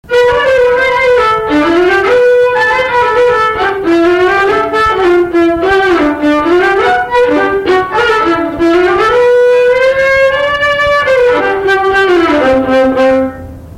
Polka
Instrumental
danse : polka
Pièce musicale inédite